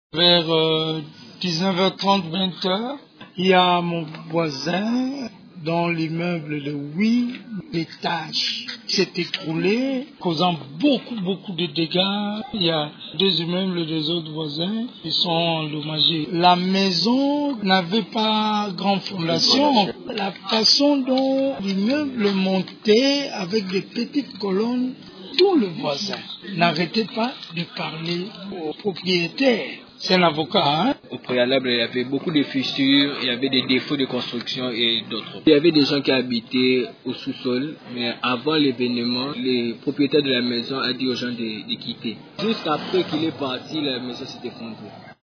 Ecoutez les témoignages des voisins: